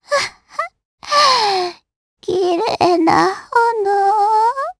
Pansirone-Vox_Dead_jp.wav